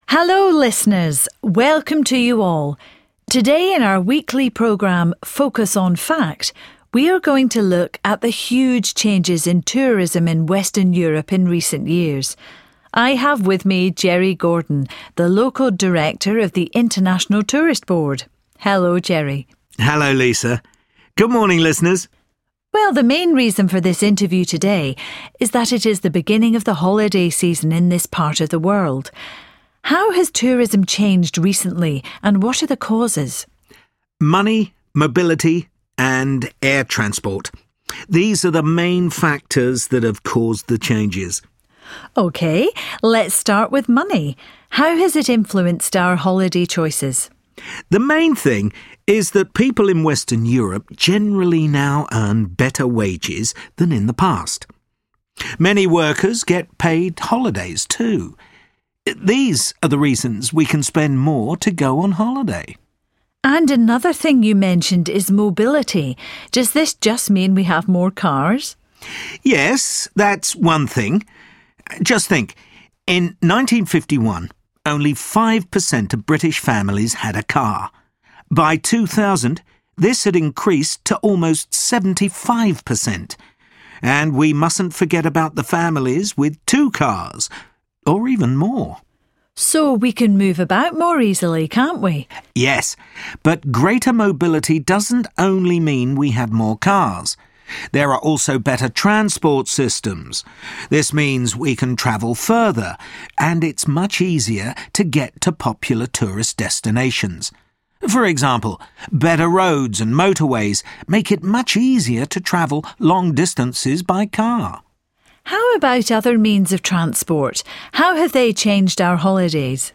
Through listening to an interview, students are introduced to how money, mobility and air travel have affected tourism in Western Europe.